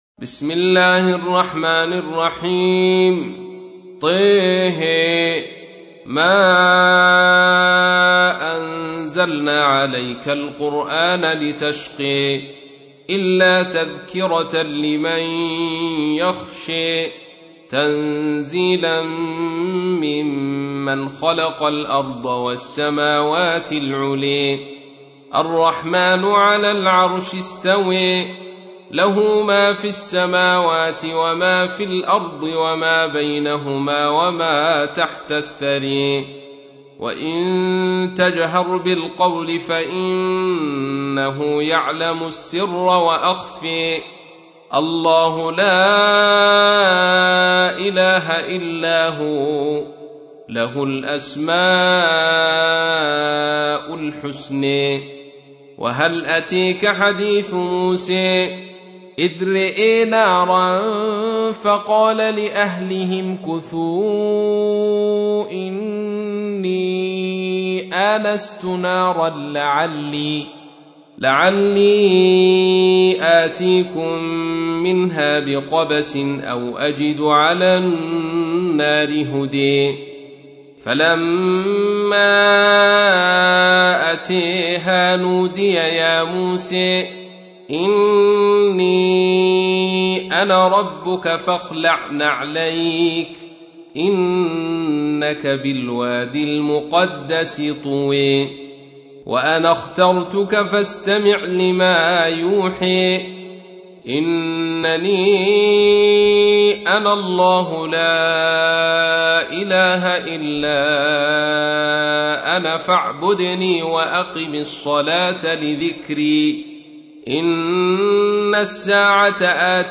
تحميل : 20. سورة طه / القارئ عبد الرشيد صوفي / القرآن الكريم / موقع يا حسين